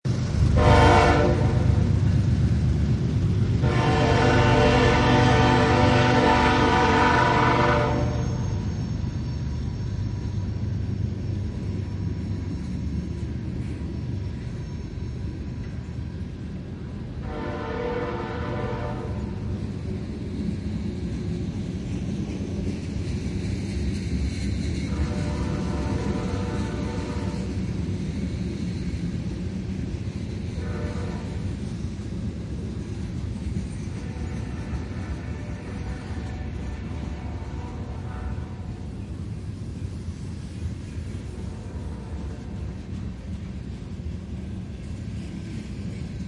Bnsf Loud Train